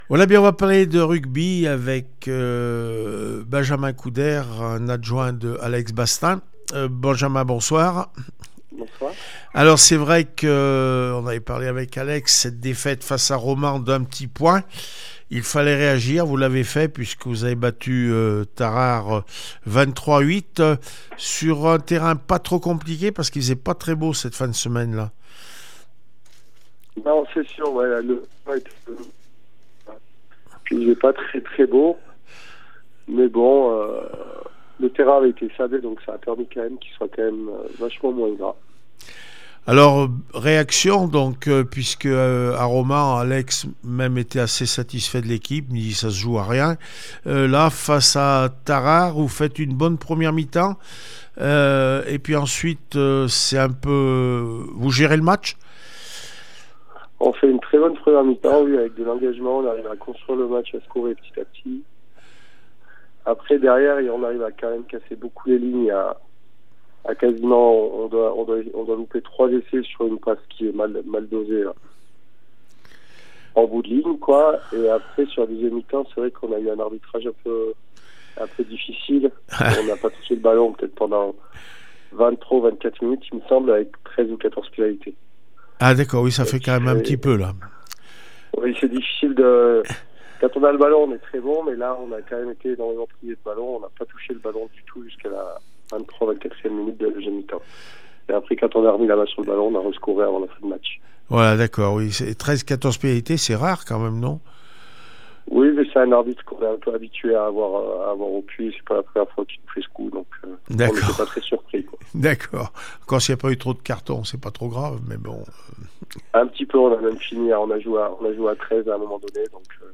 16 janvier 2023   1 - Sport, 1 - Vos interviews